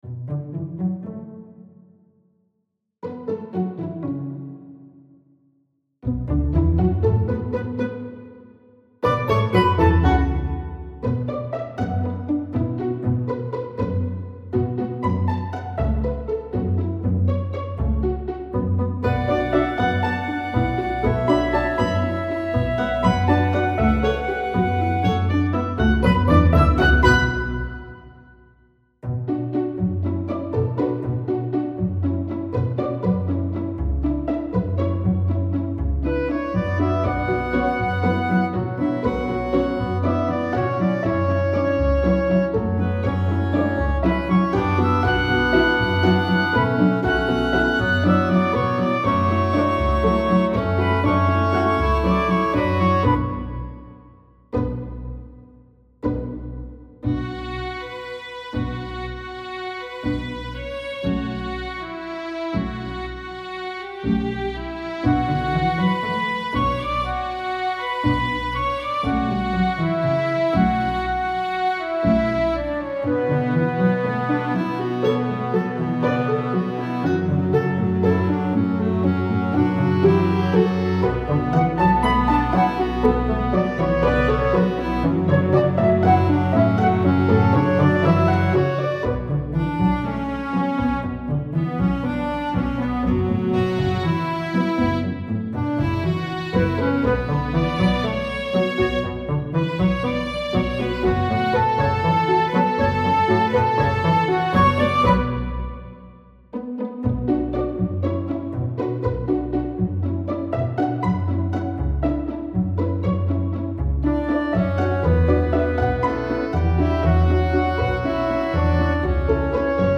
String Orchestra + Woodwind – Intermediate